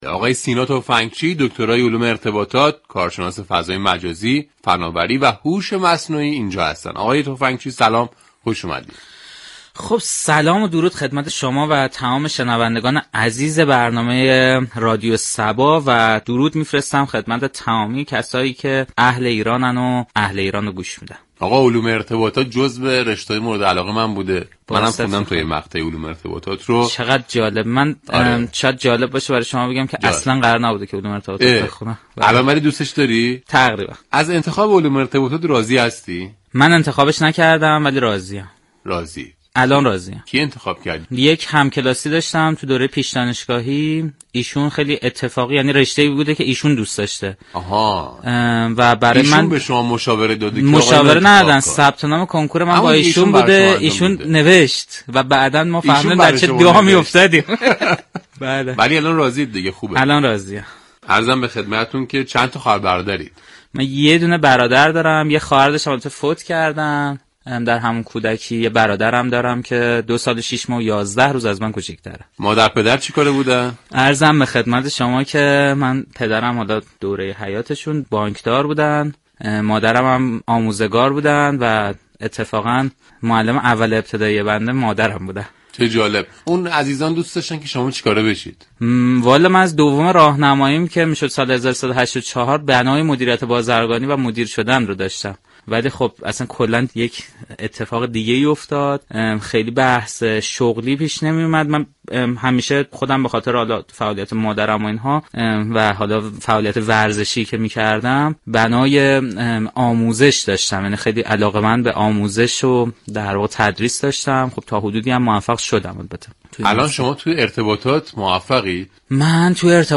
اهالی علم و فرهنگ در ویژه برنامه انتخاباتی «اهل ایرانم» از ملزومات یك انتخاب درست می‌گویند